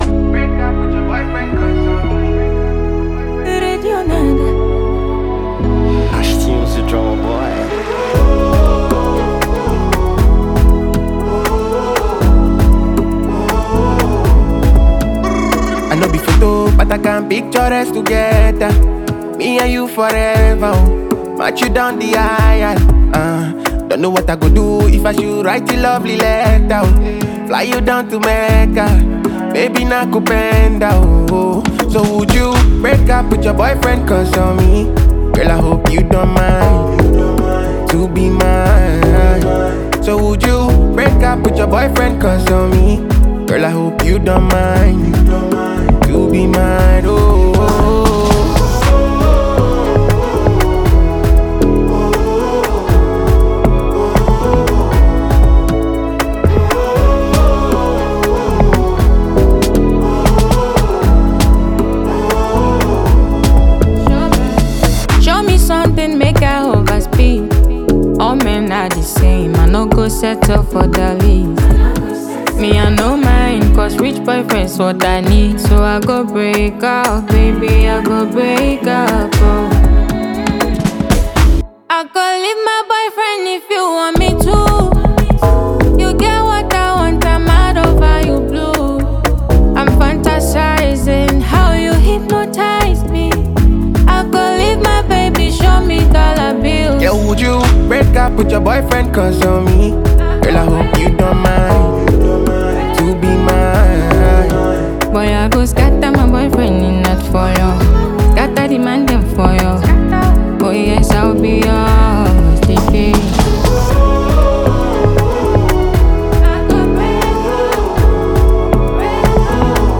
Ghana Music
New tune from Afro-Highlife Ghanaian artiste